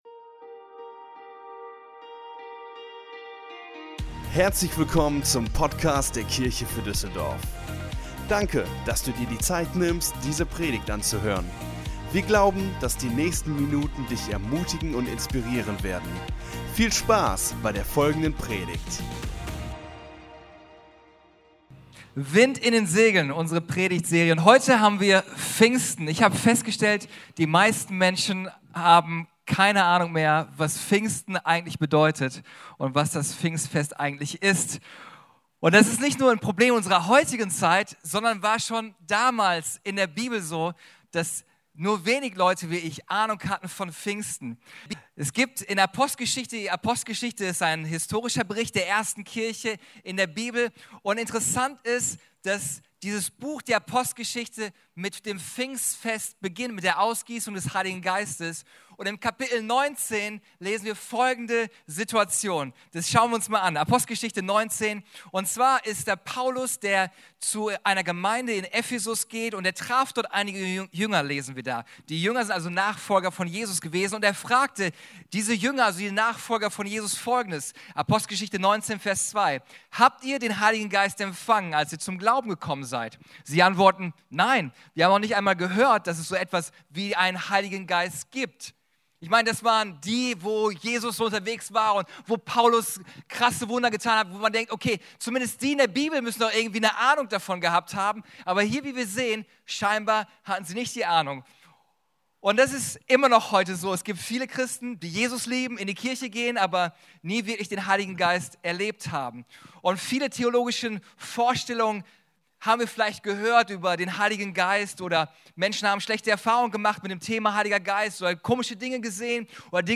Der vierte und letzte Teil unserer Predigtreihe "Wind in den Segeln". Wir alle brauchen regelmäßig frischen Wind und neue Kraft, die unser Leben belebt. In dieser Serie wollen wir uns anschauen, wie wir das in unserem Leben erfahren können.